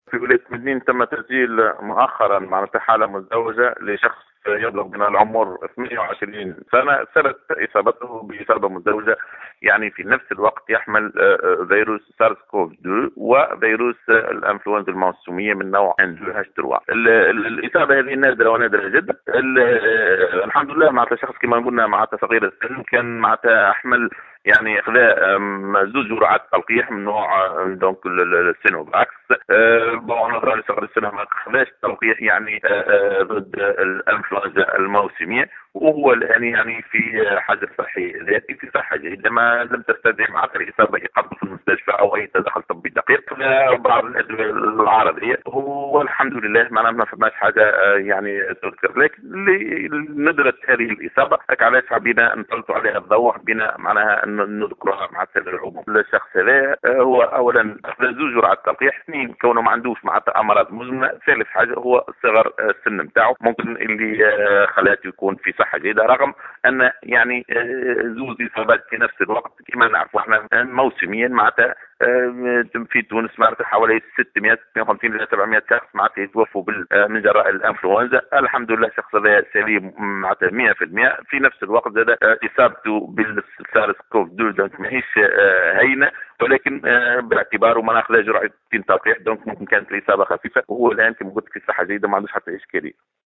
وبين المتحدث في اتصال هاتفي للجوهرة أف أم، أنّ المصاب يخضع حاليا للعزل الصحي الذاتي وهو في حالة جيدة، نظرا لتلقيه جرعتي تلقيح مضاد لكورونا، وكذلك لصغر سنه، حسب تعبيره.